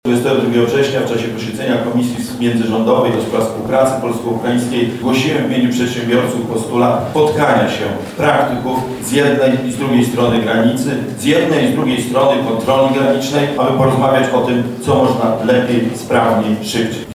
Wydarzenie odbywa się w Wyższej Szkole Przedsiębiorczości i Administracji w Lublinie.
• mówi prezes polsko-ukraińskiej izby gospodarczej Jacek Piechota.